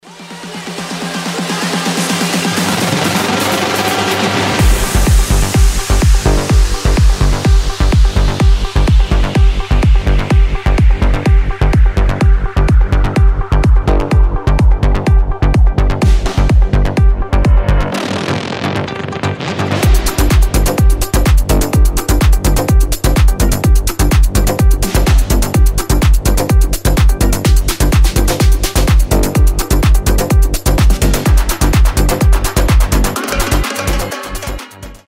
• Качество: 320, Stereo
progressive house